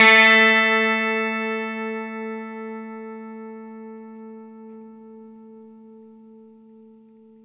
R12NOTE A +.wav